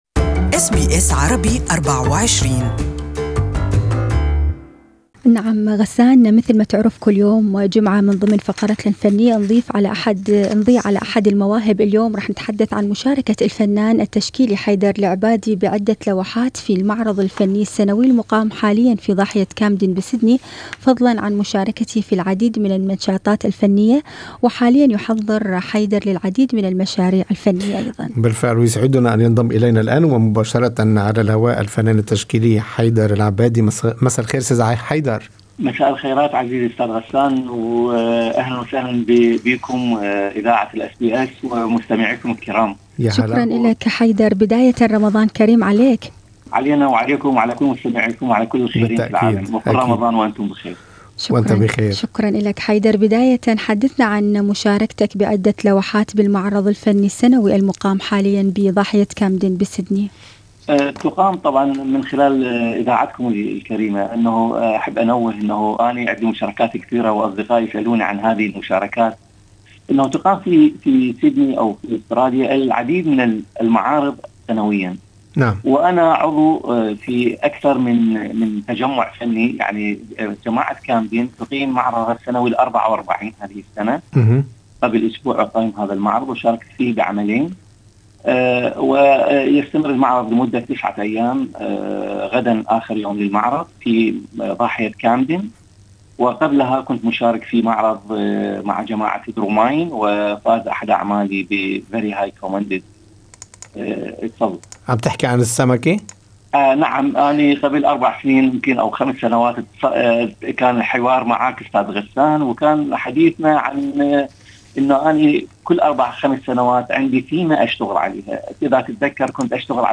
المزيد في لقاءِ مباشر